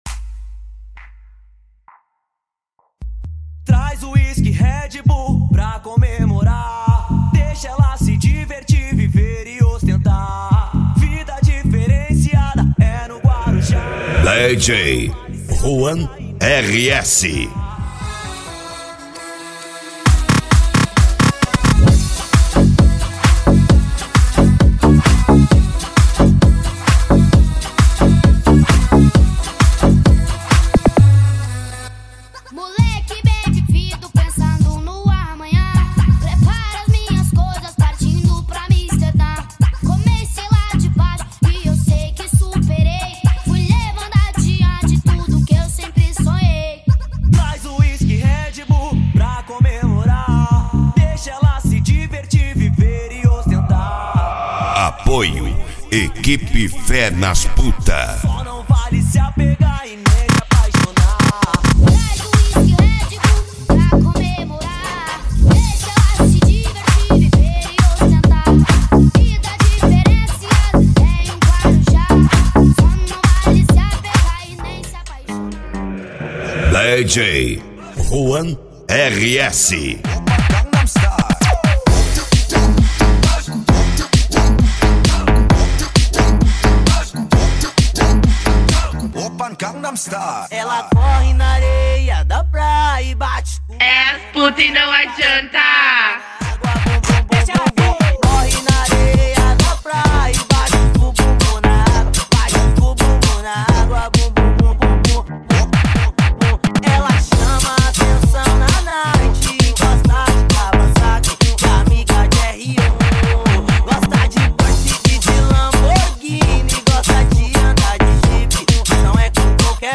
Eletro Funk